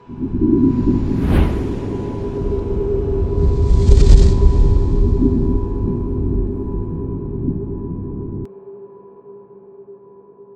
“Avalanche Spell Cast” Created in Sound Booth CS4 By
cast_avelanch_spell2.wav